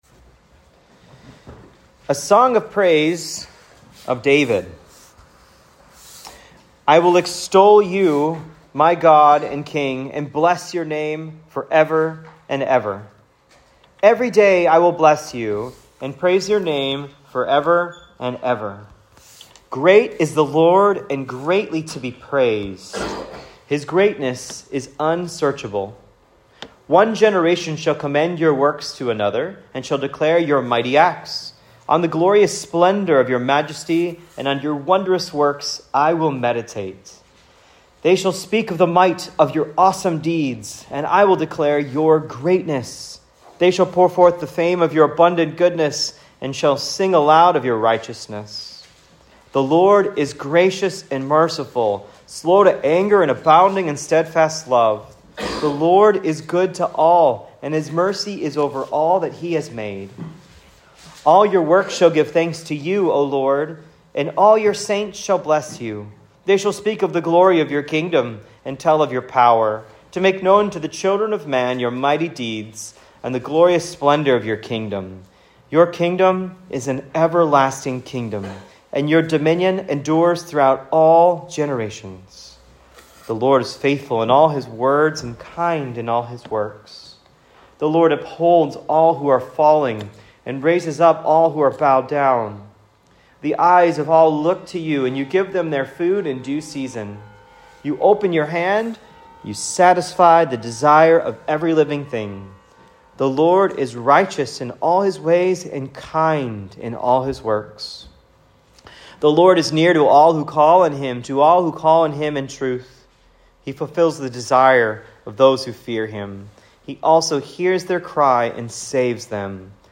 Here’s my notes and audio for “Great is the LORD!” sermon based on Psalm 145, preached at Cross of Christ Fellowship in Naperville.